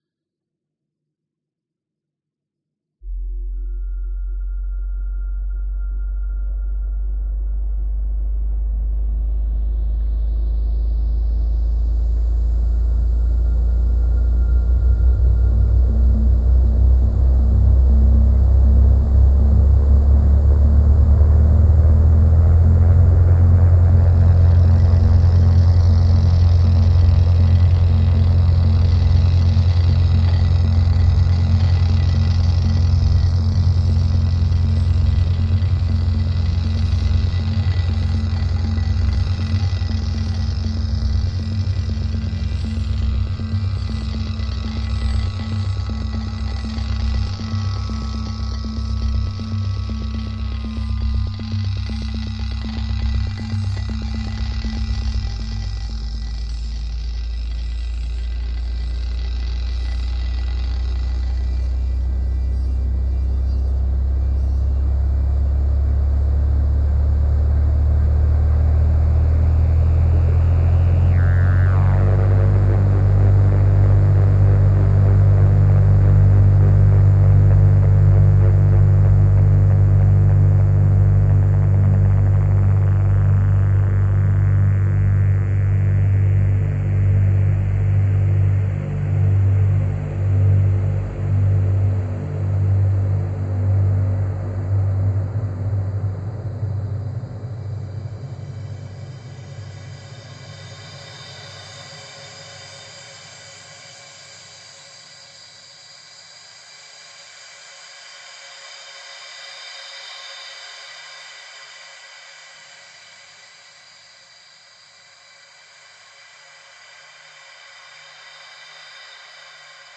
sound art